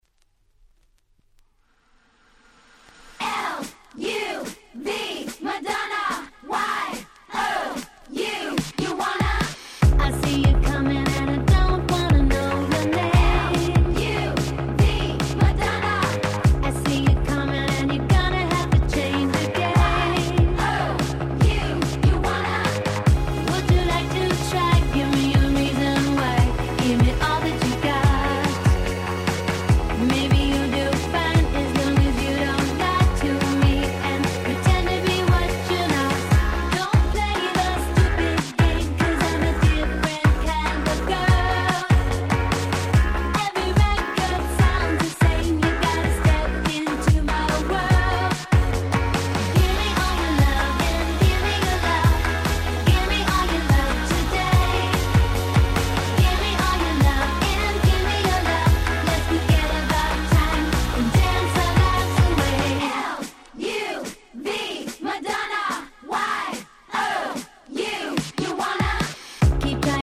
12' Super Hit R&B/Pops !!